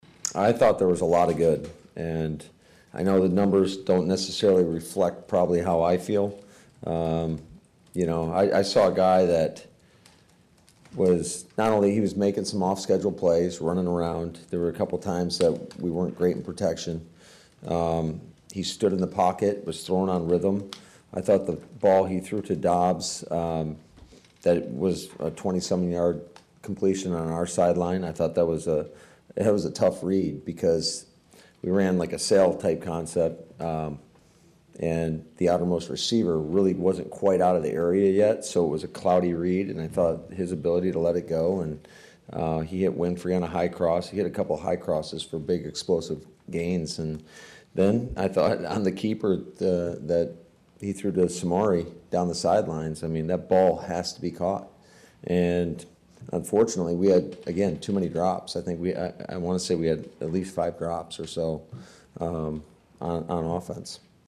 While the numbers won’t put him even in the Pre-Season Hall of Fame,  Head Coach Matt LaFleur opened his post game press conference with strong words of encouragement.